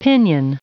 Prononciation du mot pinion en anglais (fichier audio)
Prononciation du mot : pinion